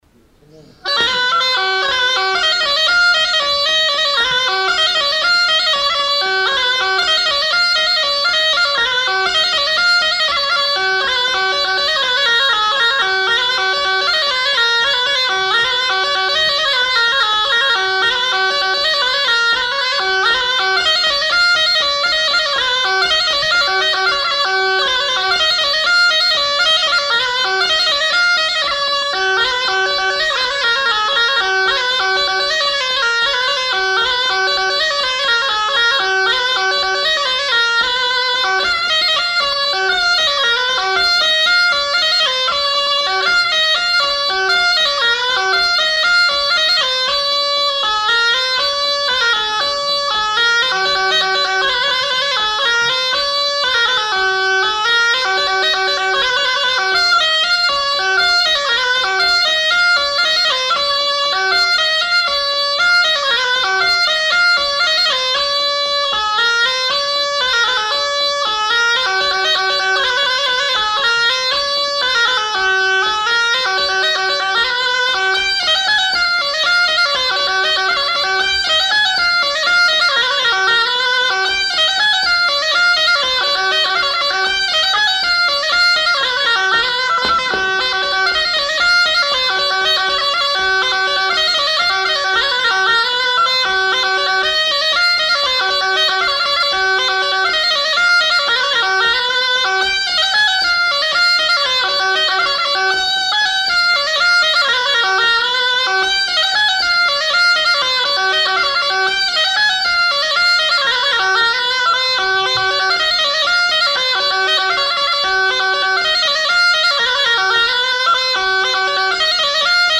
Lieu : Marvejols
Genre : morceau instrumental
Instrument de musique : cabrette
Danse : bourrée